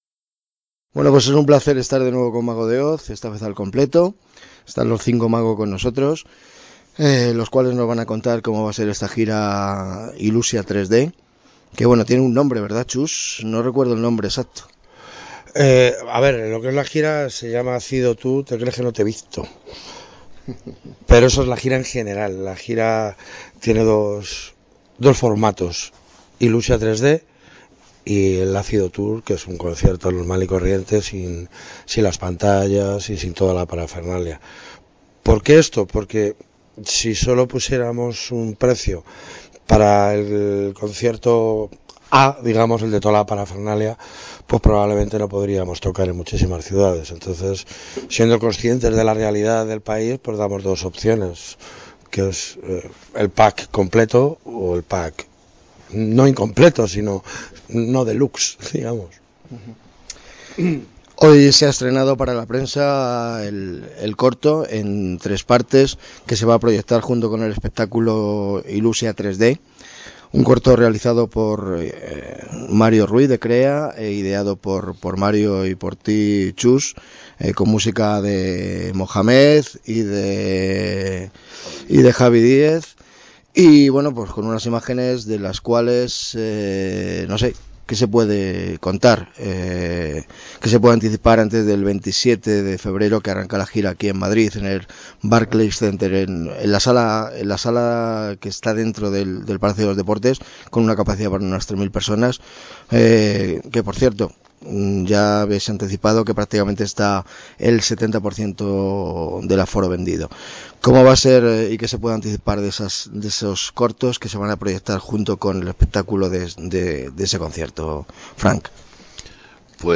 Entrevista con Txus, Frank, Mohamed, Carlitos y Zeta de MÄGO DE OZ. Todo sobre la Gira Ilussia 3D